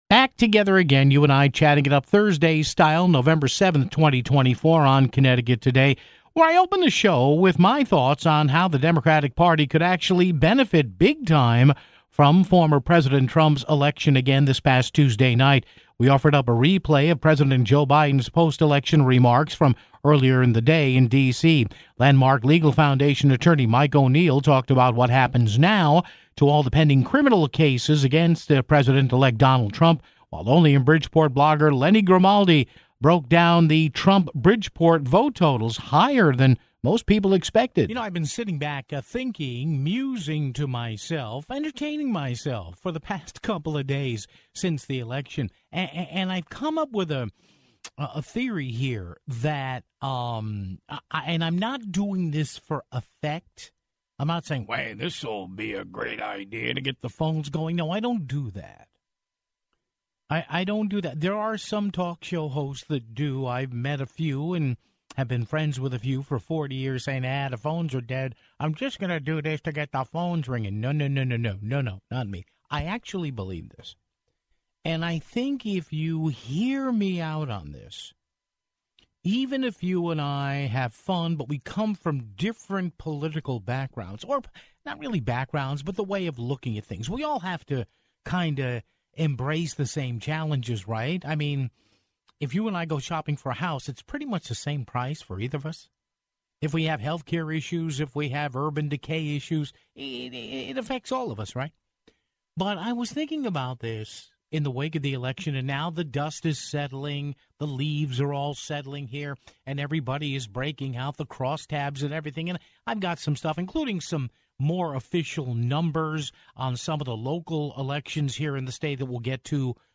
We ran a replay of President Joe Biden's post-election remarks, from earlier in the day, in Washington, D.C. (15:18).